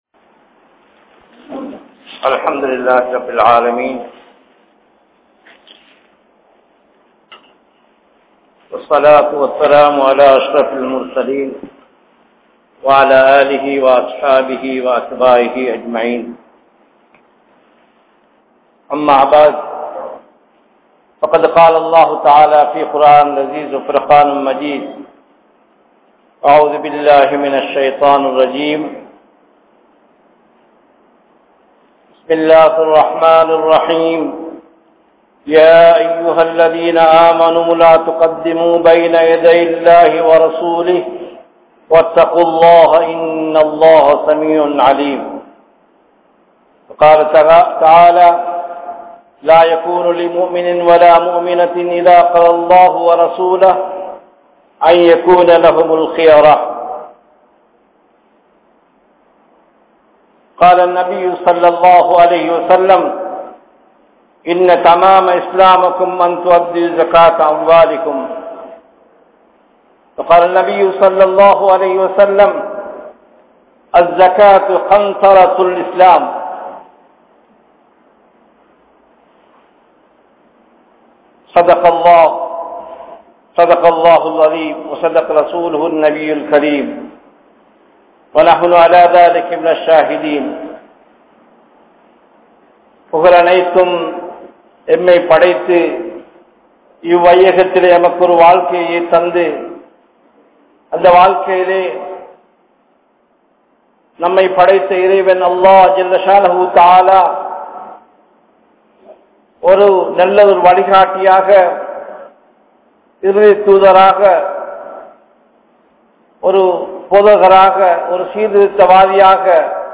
Zakath | Audio Bayans | All Ceylon Muslim Youth Community | Addalaichenai
Kandy, Udunuwara Jumuah Masjith